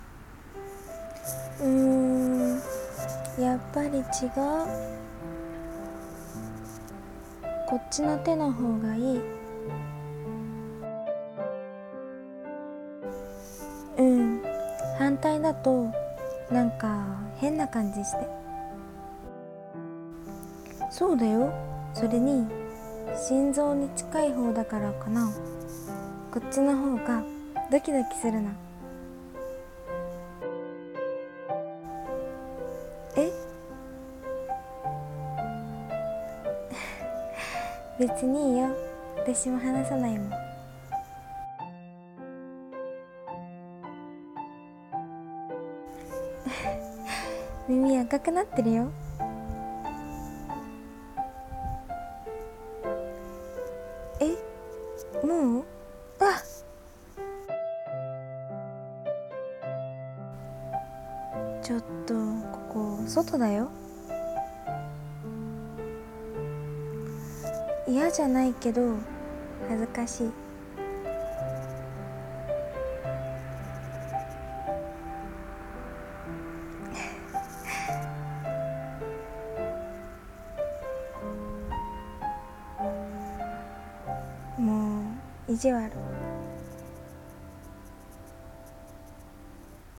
声劇【離さない手】※恋愛コラボ声劇 朗読